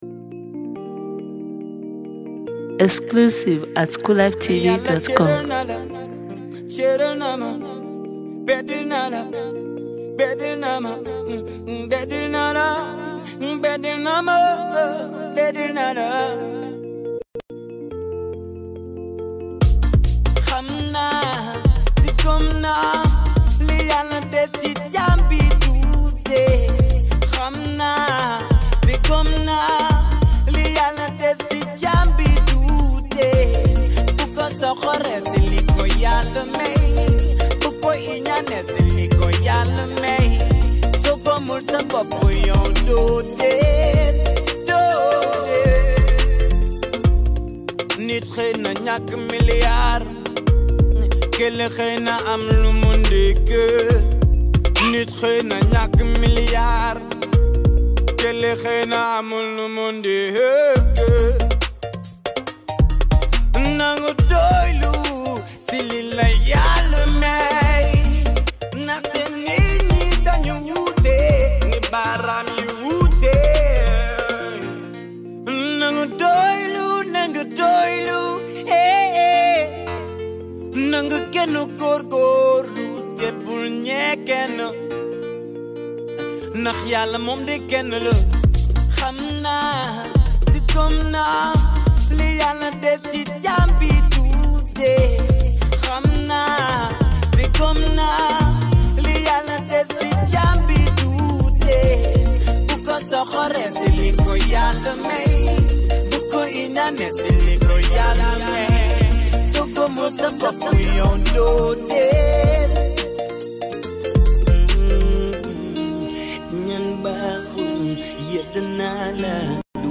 is a raggae artist based in Banjul